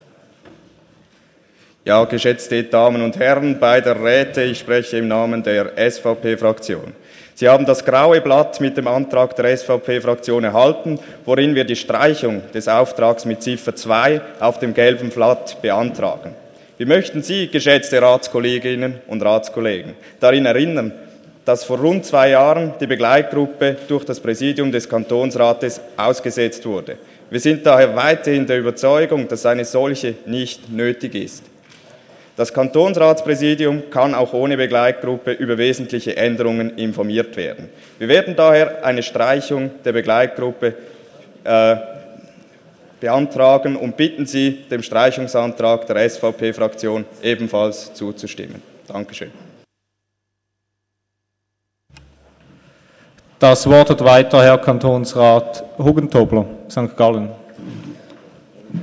Session des Kantonsrates vom 12. und 13. Juni 2017